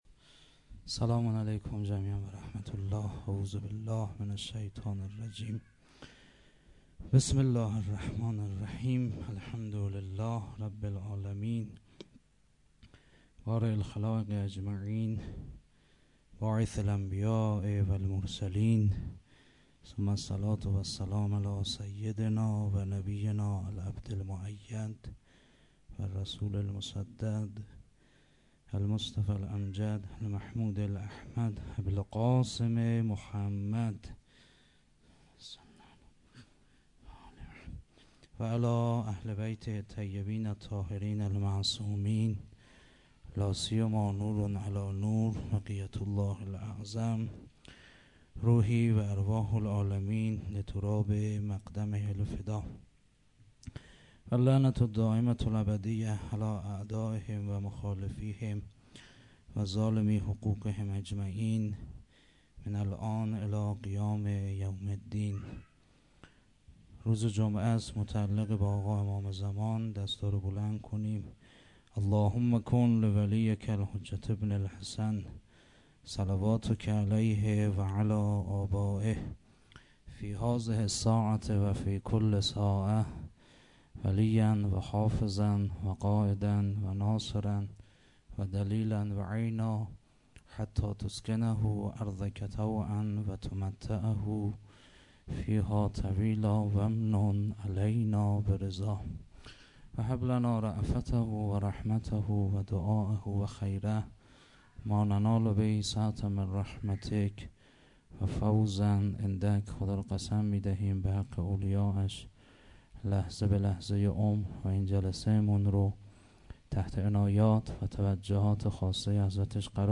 0 0 سخنران